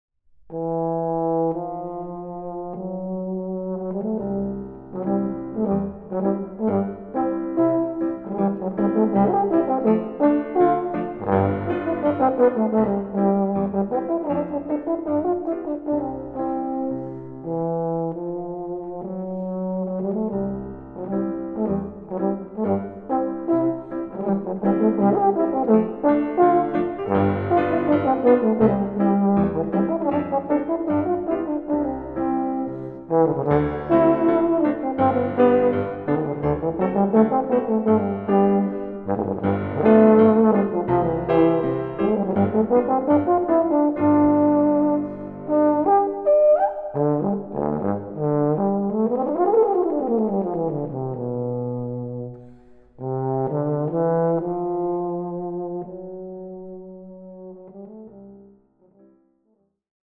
Arr. for Bass trumpet and Piano